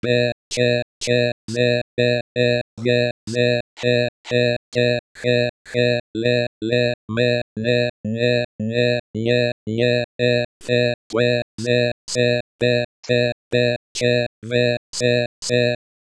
eSpeak NG is an open source speech synthesizer that supports more than hundred languages and accents.